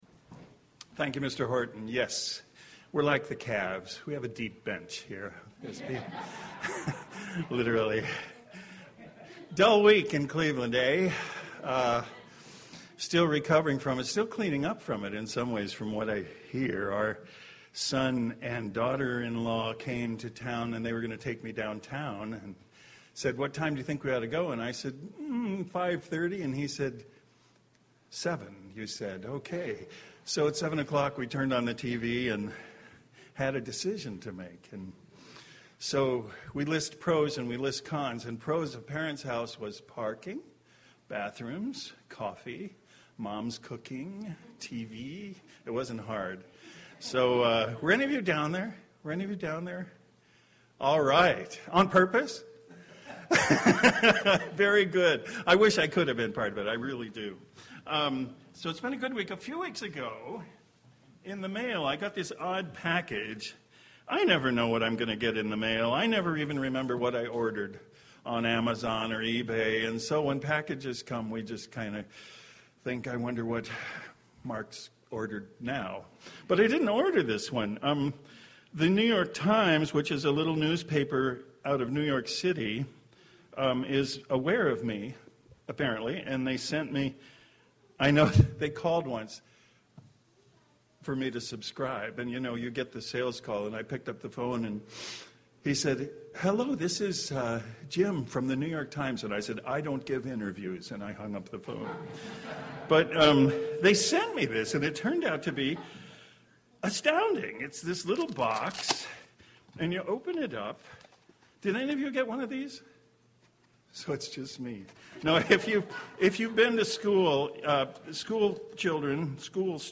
Given in Cleveland, OH
UCG Sermon Studying the bible?